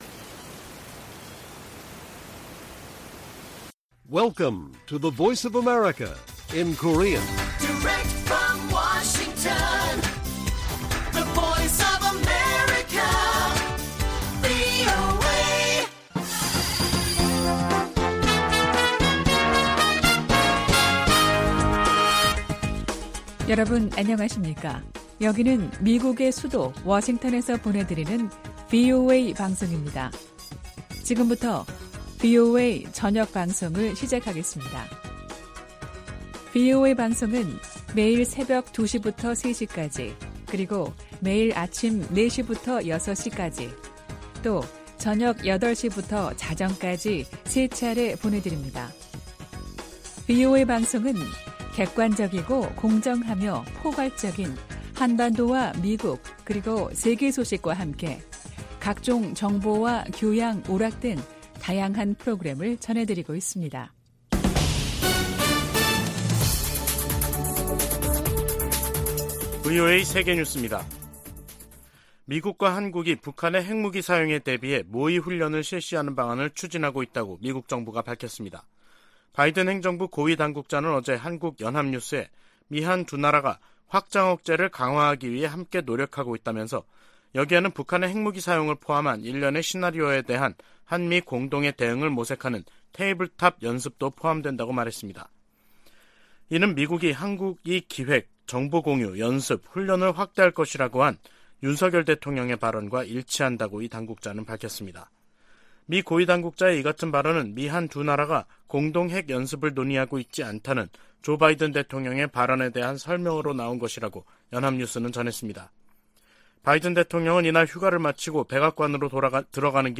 VOA 한국어 간판 뉴스 프로그램 '뉴스 투데이', 2023년 1월 3일 1부 방송입니다. 유럽연합과 영국, 캐나다 등이 북한에 군사적 긴장 고조행위를 중단하고 비핵화 대화에 복귀하라고 촉구했습니다. 조 바이든 미국 대통령에게는 북한 핵 문제를 비롯한 세계적인 핵무기 위험이 새해에도 주요 외교적 도전이 될 것이라고 미국 외교전문지가 지적했습니다.